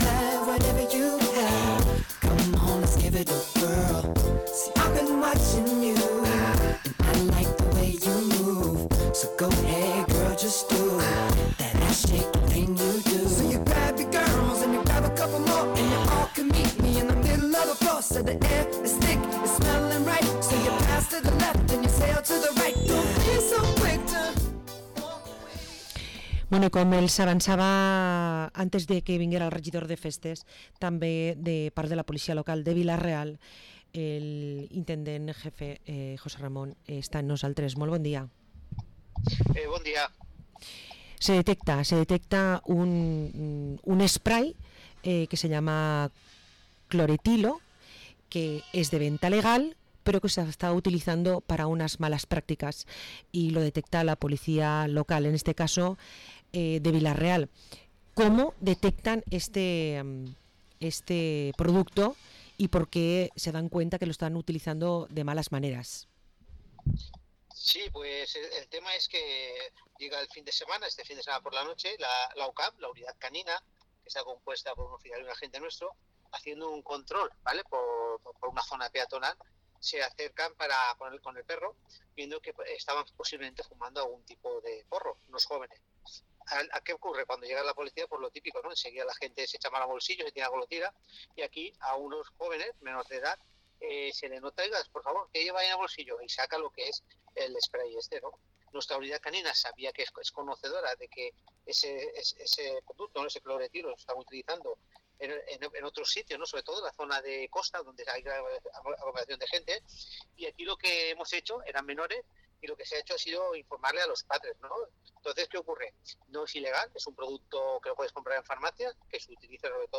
Entrevista al Intendente de la Policía Local de Vila-real, José Ramón Nieto